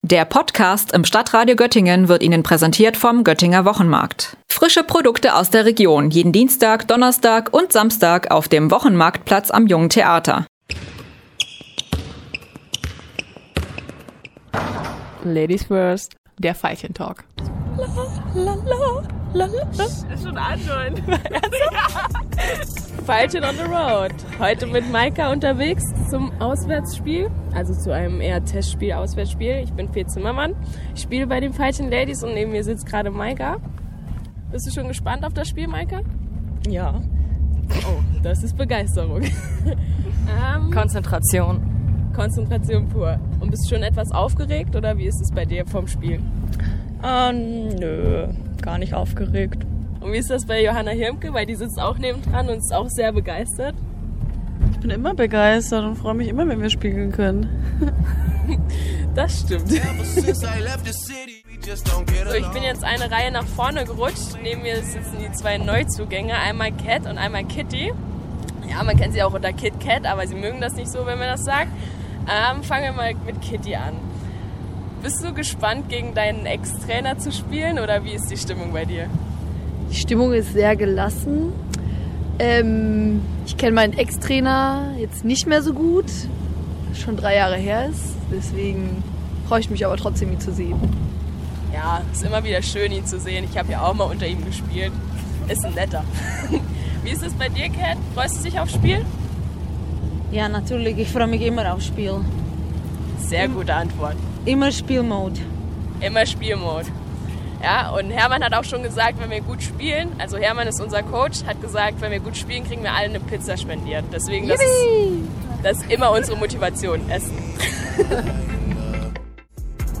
In der Fahrt zum Testspiel nach Halle hat sie die Mitspielerinnen über die Pläne für die freien Tage und das anstehende Testspiel ausgequetscht.